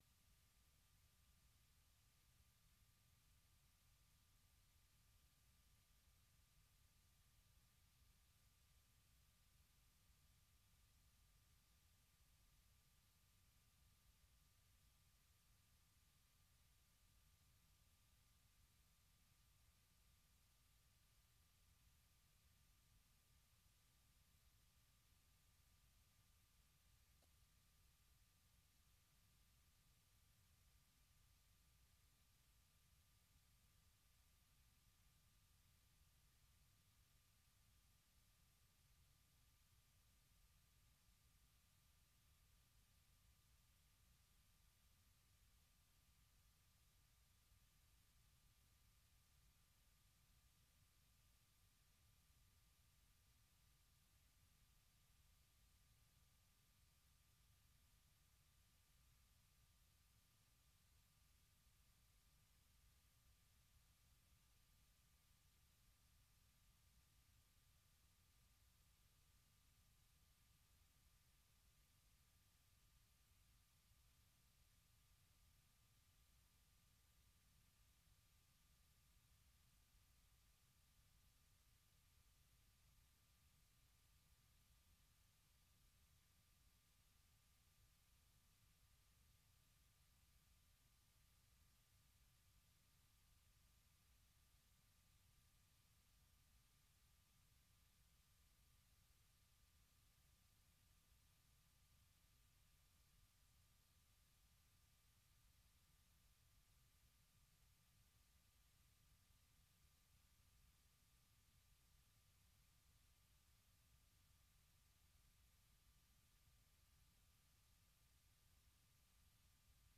“A votre avis” c’est l’émission qui donne la parole aux auditeurs de VOA Afrique tous les jours à 18h TU, du lundi au vendredi.